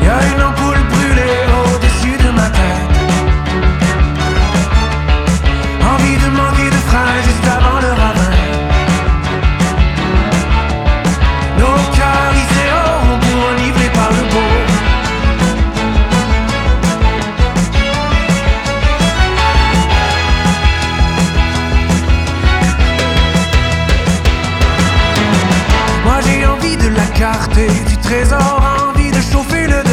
Genre: Musique francophone